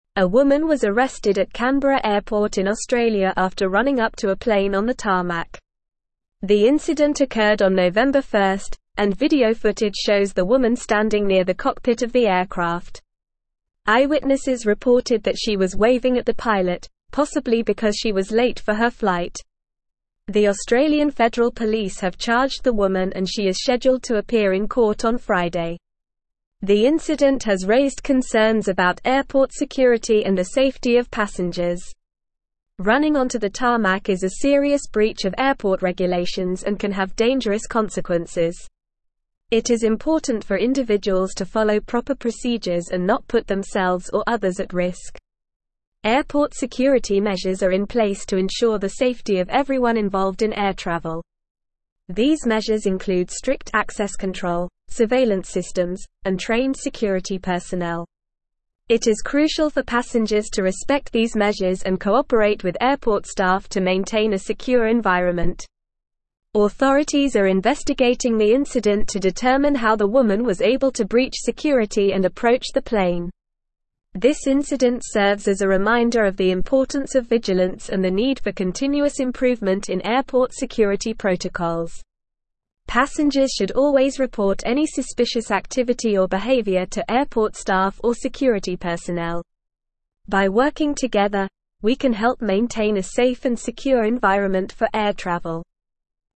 Normal
English-Newsroom-Advanced-NORMAL-Reading-Woman-Arrested-for-Running-onto-Tarmac-at-Canberra-Airport.mp3